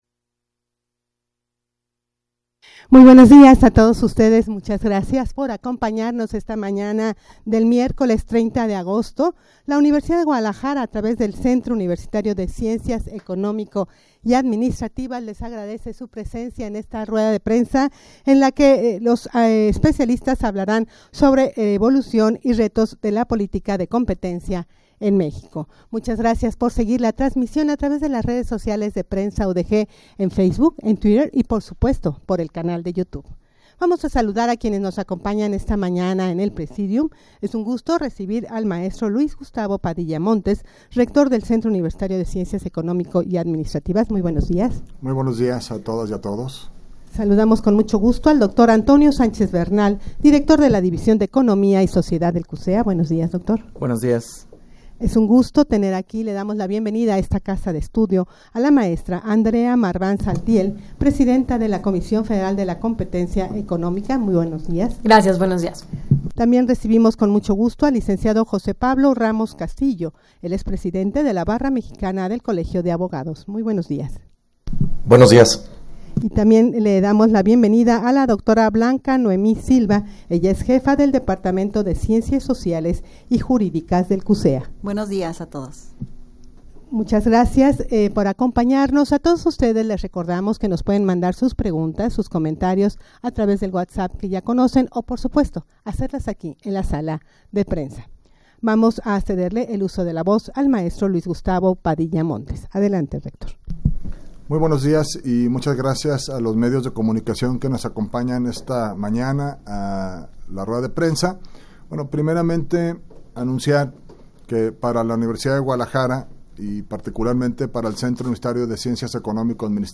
Audio de la Rueda de Prensa
rueda-de-prensa-evolucion-y-retos-de-la-politica-de-competencia-en-mexico.mp3